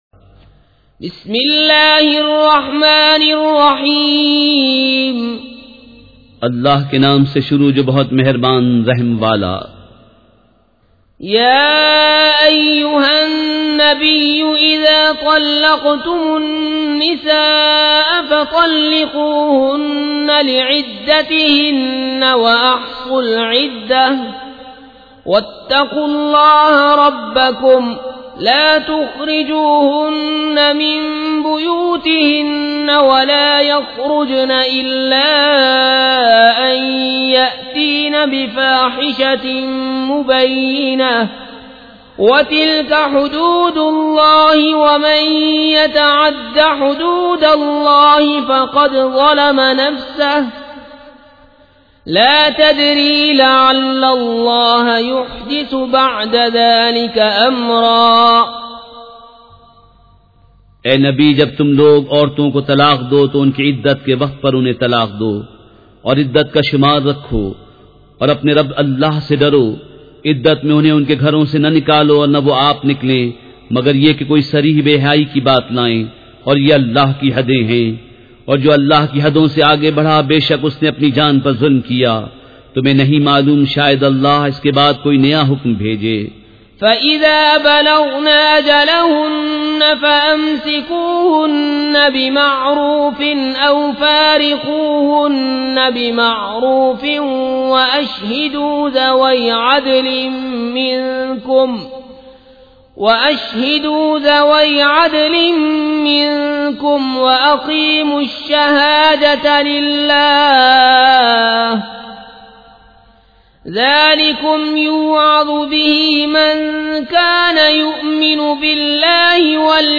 سورۃ الطلاق مع ترجمہ کنزالایمان ZiaeTaiba Audio میڈیا کی معلومات نام سورۃ الطلاق مع ترجمہ کنزالایمان موضوع تلاوت آواز دیگر زبان عربی کل نتائج 1649 قسم آڈیو ڈاؤن لوڈ MP 3 ڈاؤن لوڈ MP 4 متعلقہ تجویزوآراء